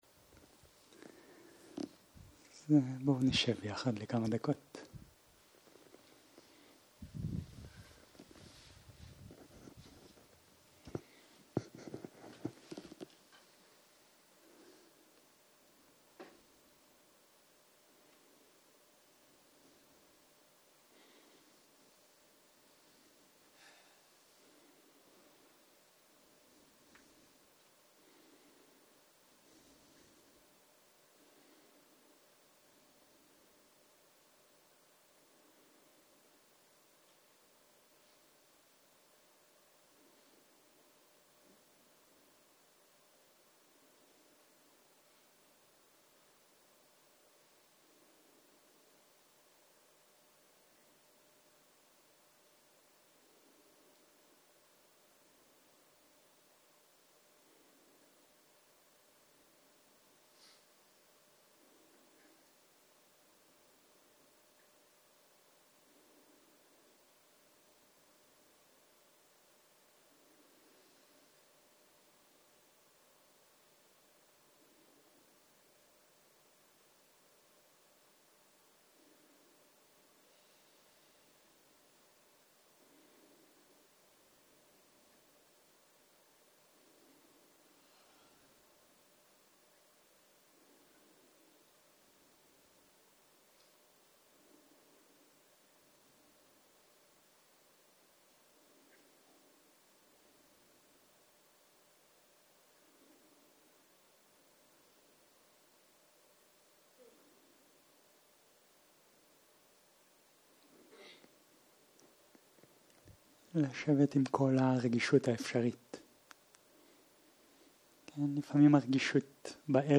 שיחת דהרמה